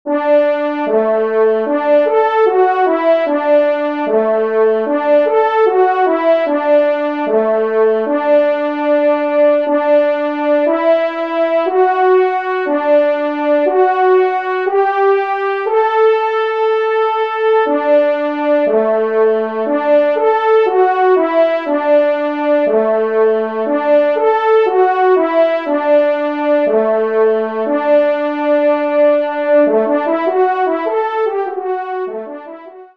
Genre :  Divertissement pour Trompe ou Cor
1e Trompe